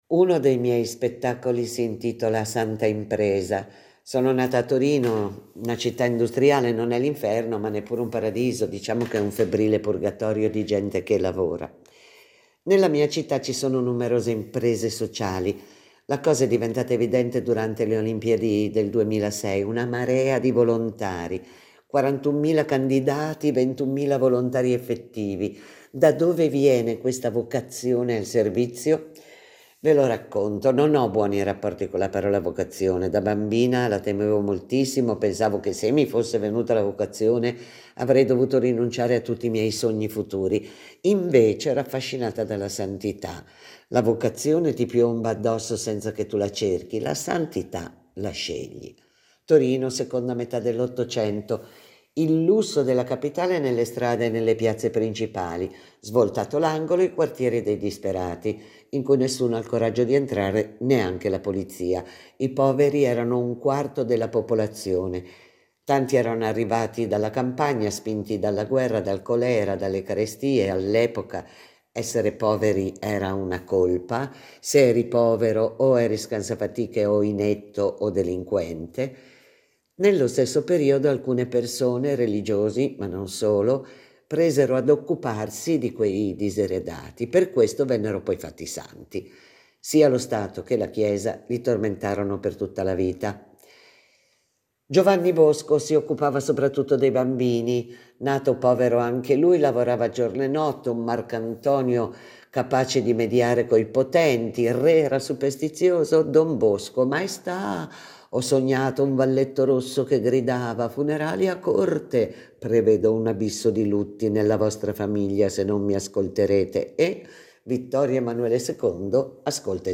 Tra le protagoniste ancora una volta un’appassionata narratrice: Laura Curino, storica interprete del teatro d’impegno civile. Sarà lei ad accompagnarci ogni giorno alle 18, attraverso cinque momenti importanti della sua vita.